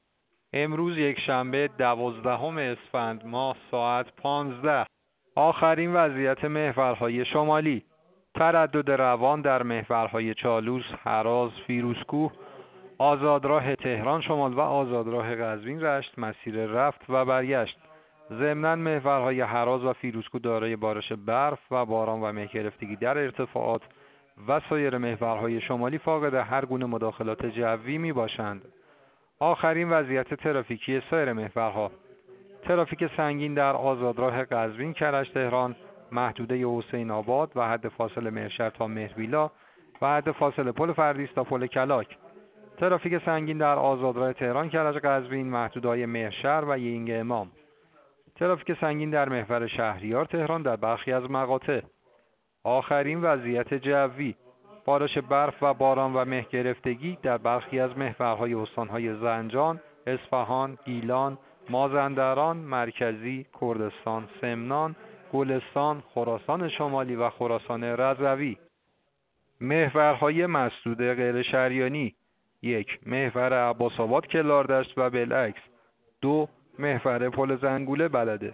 گزارش رادیو اینترنتی از آخرین وضعیت ترافیکی جاده‌ها ساعت۱۵ دوازدهم اسفند؛